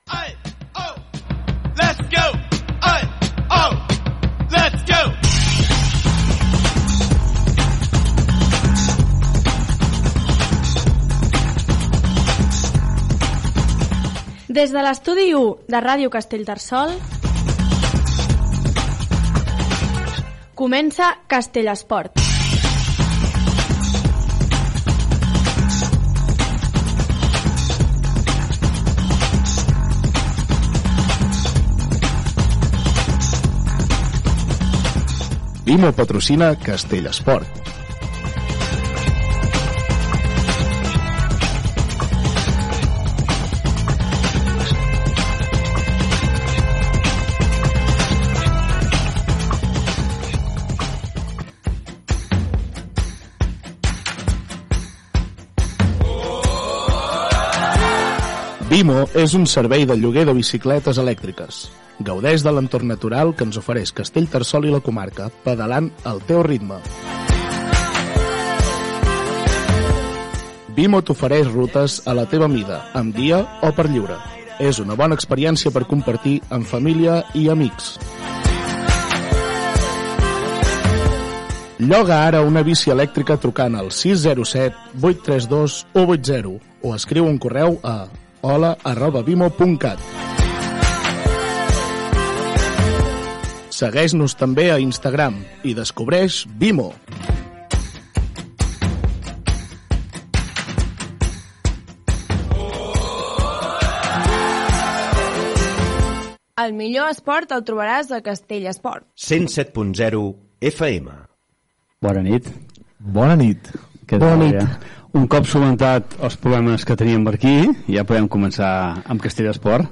Careta del programa, publicitat, indicatiu del programa, presentació, repàs a l'inici de la temporada esportiva i rècord a la competició de futbol sala feta a l'estiu a la localitat.
Esportiu